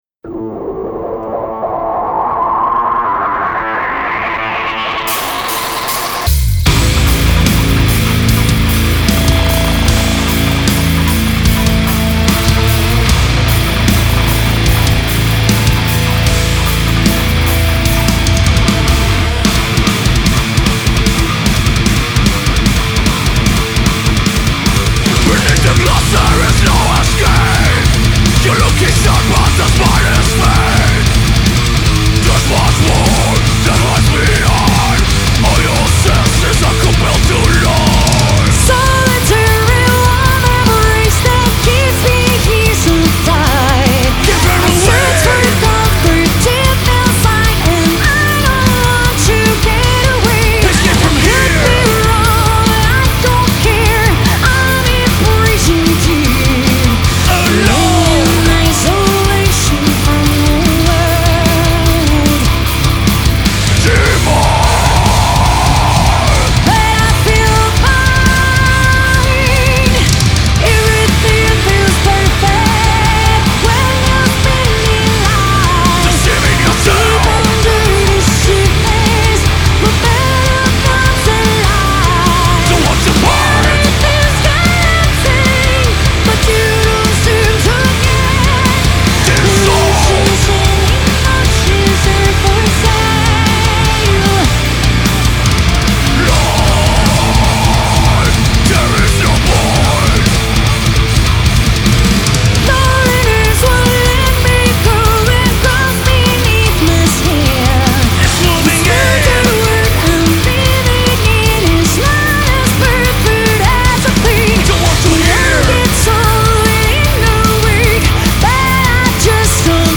Genre : Rock, Hard Rock, Metal